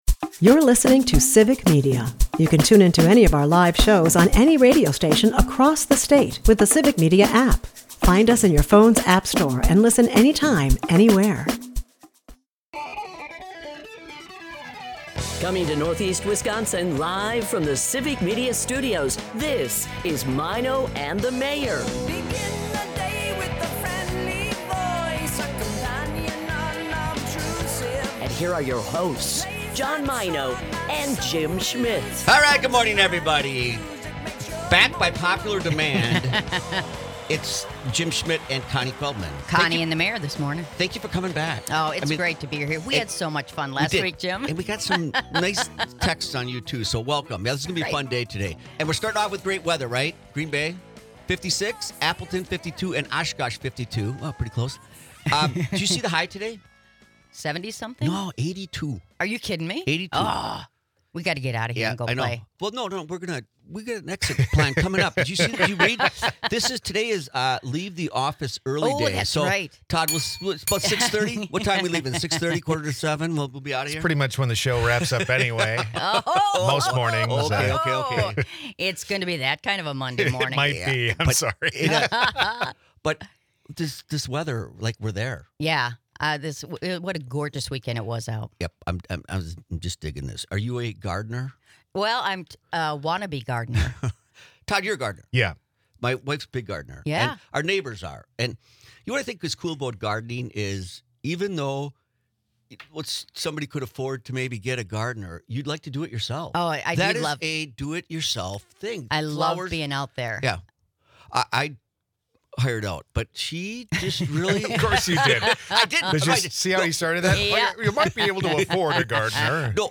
They discuss the quirks of gardening, including DIY efforts versus hiring help, and the satisfaction of indulging in nature’s bounty. The conversation meanders through neighborhoods' importance, community events like 'Breakfast on the Farm,' and the rich cultural tapestry of the region, including the Mulva Center's role. Amidst the banter, local trivia, and friendly jabs, the duo sets the stage for upcoming guests and events.